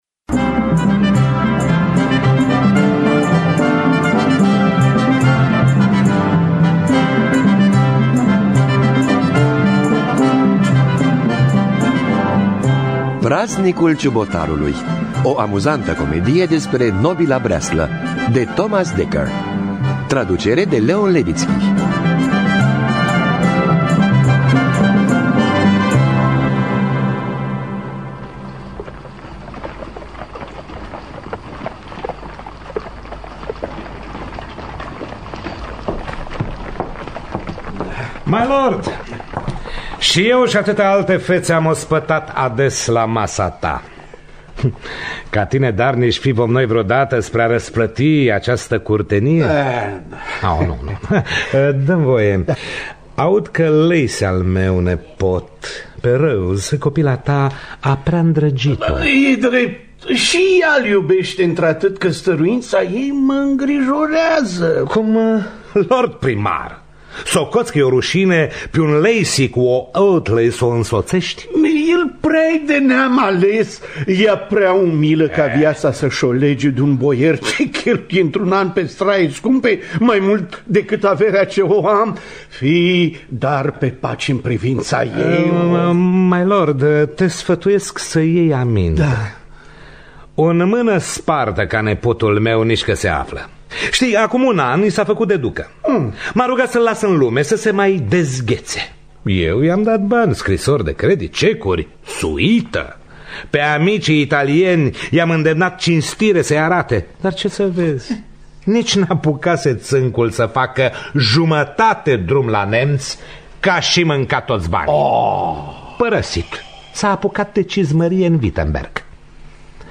Adaptare radiofonică
Muzică originală
chitară
flaut